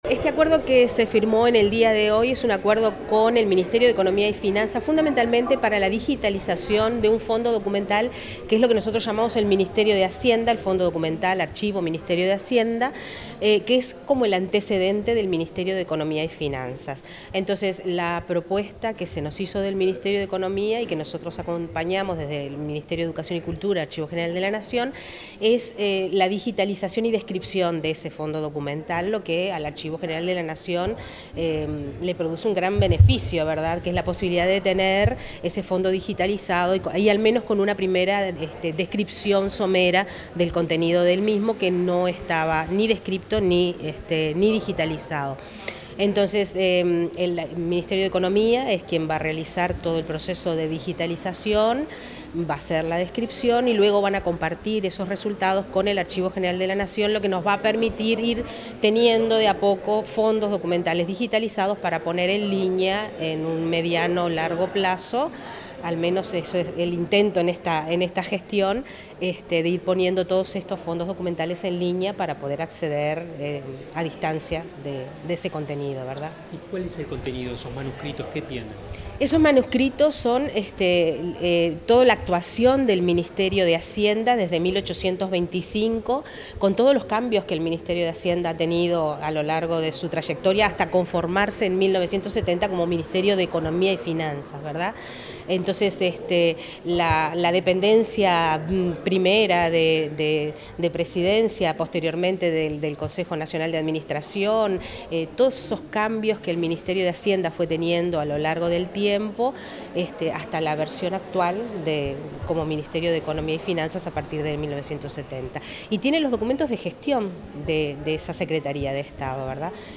Declaraciones de la directora del Archivo General de la Nación, Alejandra Villar
La directora del Archivo General de la Nación, Alejandra Villar, dialogó con los medios informativos, luego de participar en la firma de un convenio